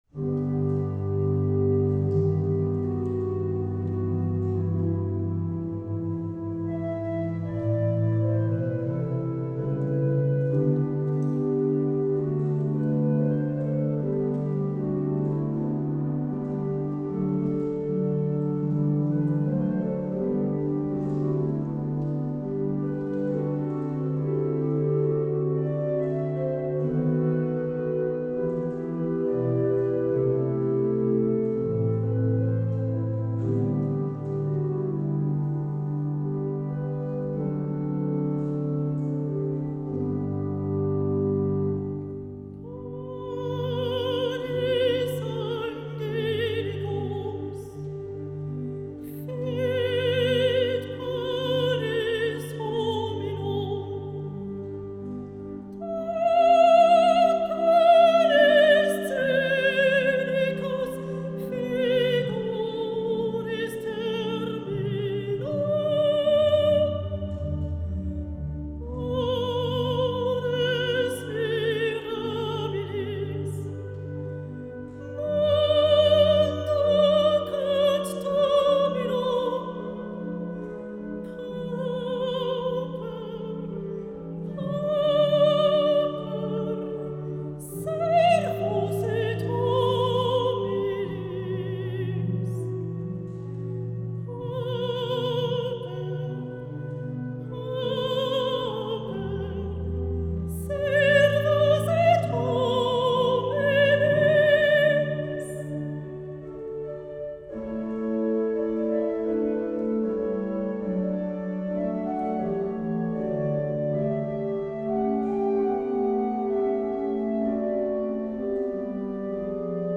Récital
orgue), Cathédrale de Lausanne